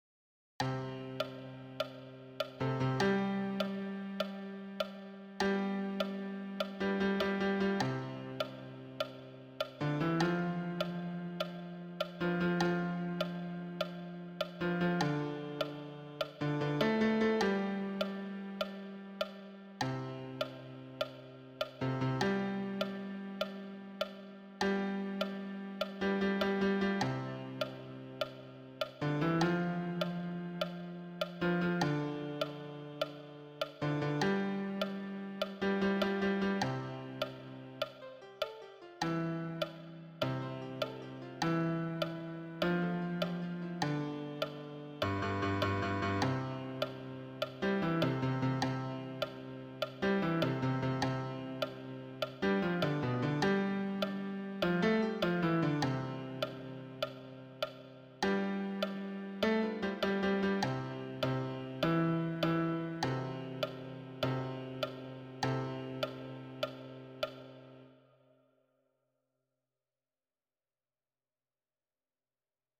Répétition SATB par voix
Basse
Non, je ne regrette rien_basse-mix.mp3